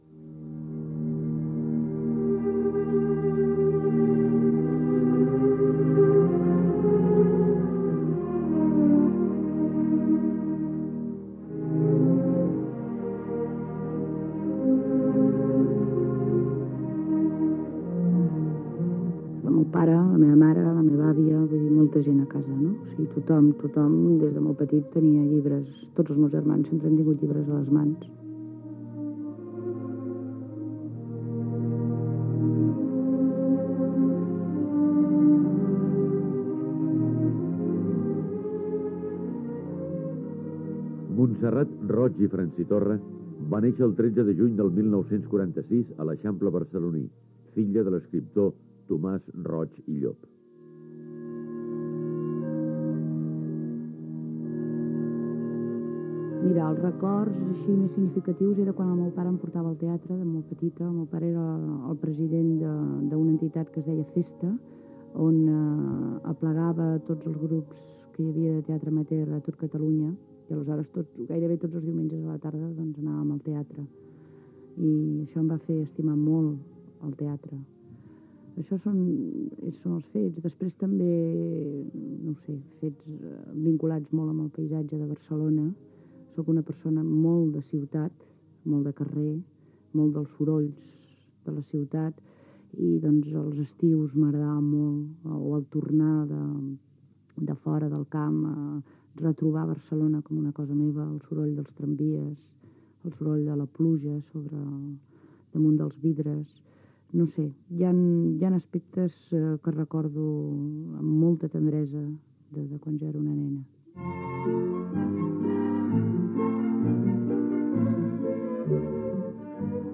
Perfil biogràfic amb fragments de veu de l'escriptora recordant la seva vida i obra